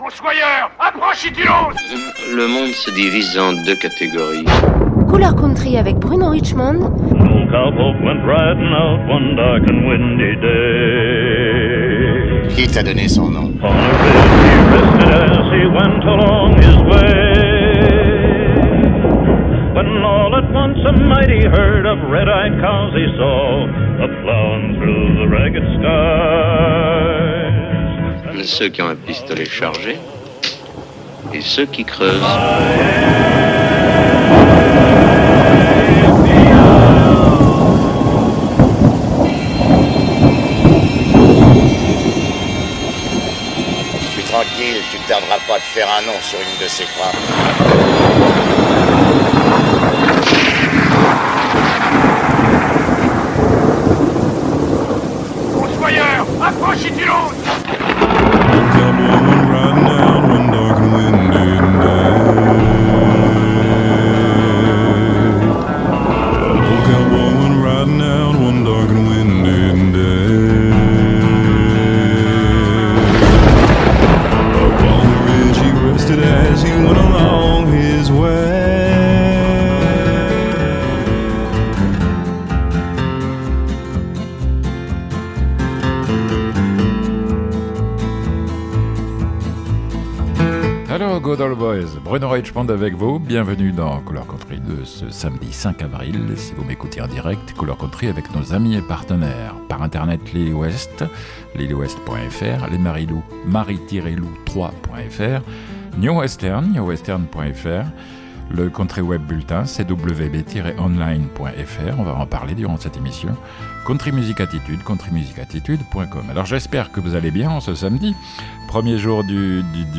Country & Western